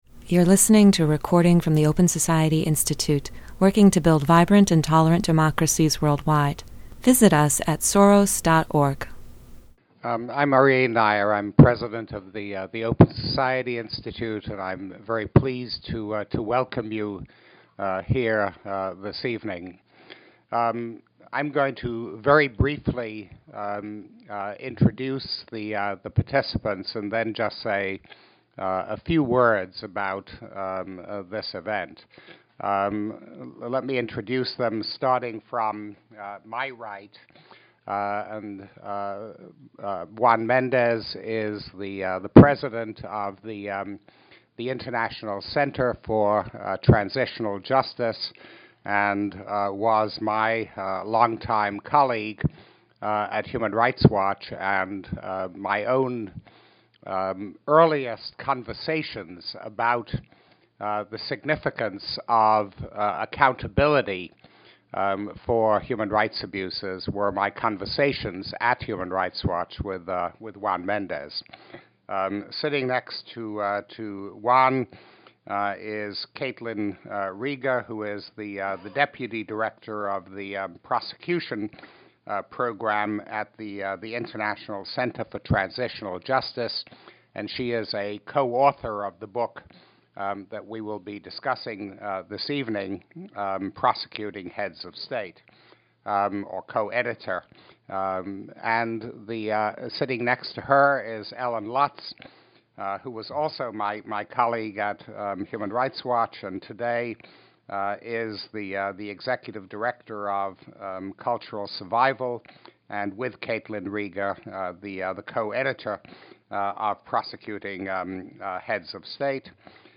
This panel event explored the issue of accountability for former heads of state who committed human rights violations and other abuses while in power.